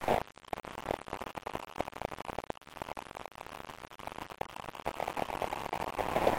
Tag: 环境 噪声 记录 样品